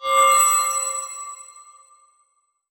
magic_shinny_high_tone_04.wav